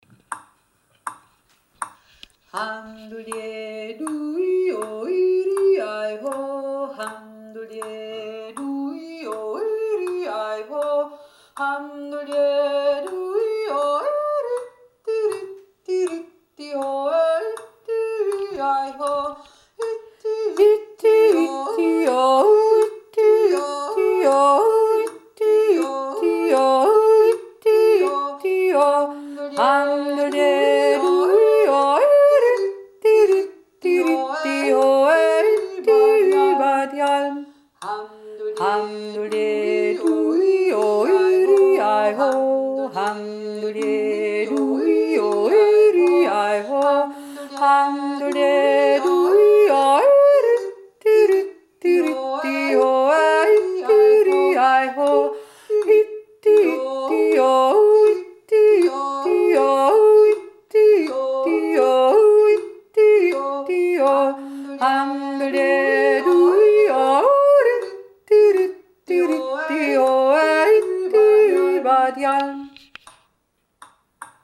Jodler del quarto incontro
1. voce